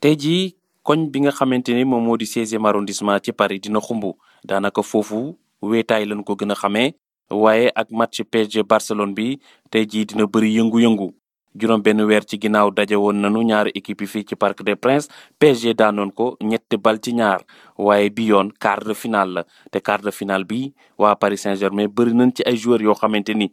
Wolof Voice Over
Sample Voice for Wolof  Language